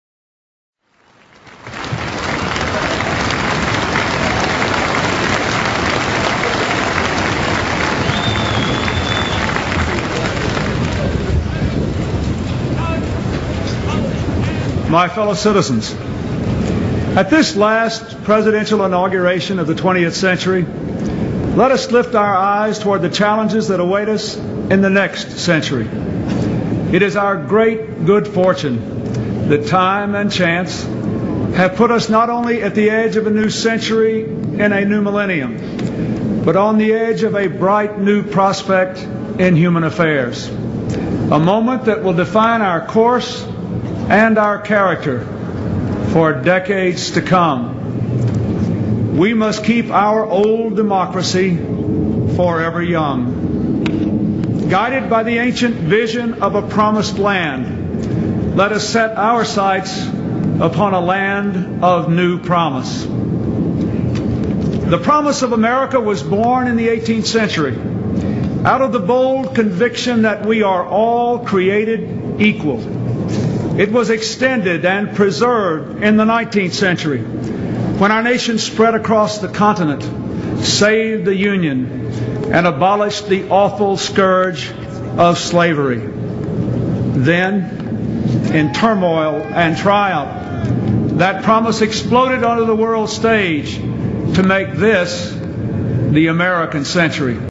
名人励志英语演讲 第11期:我们必须强大(1) 听力文件下载—在线英语听力室